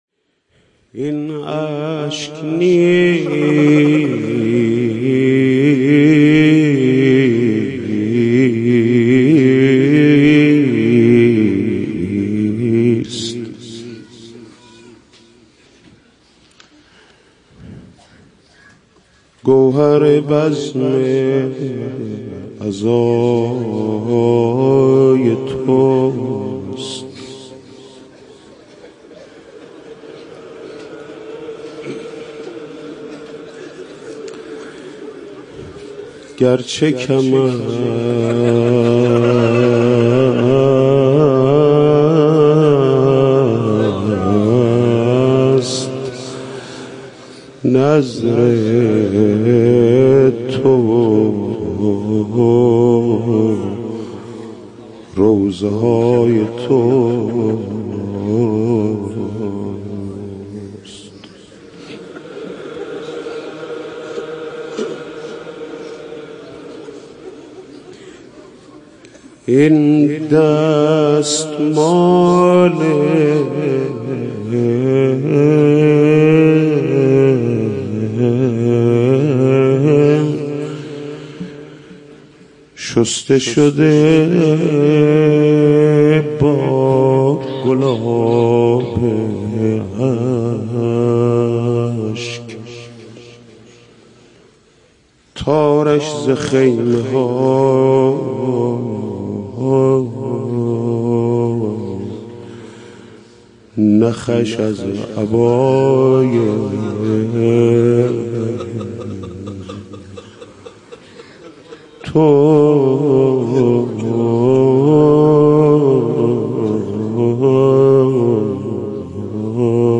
روضه شب اول محرم با صدای محمود کریمی -( این اشک نیست گوهر بزم عزای توست )
روضه